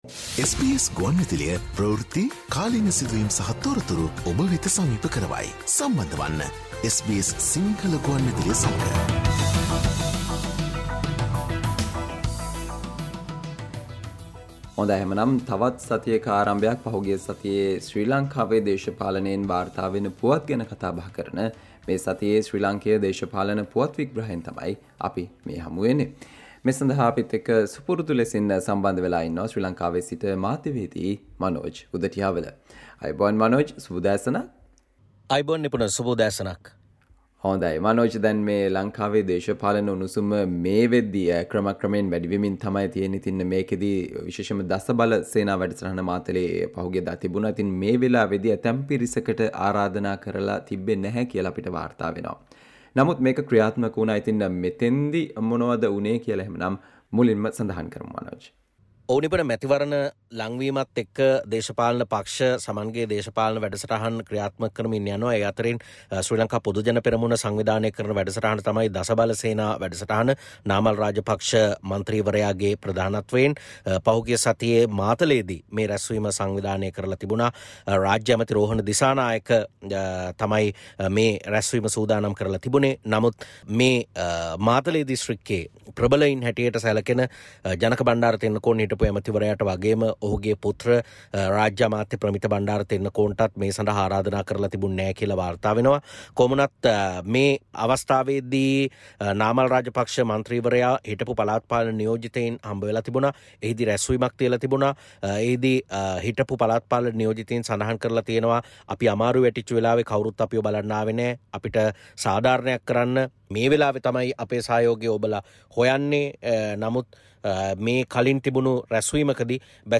SBS Sinhala radio brings you the most prominent political news highlights of Sri Lanka in this featured Radio update on every Monday.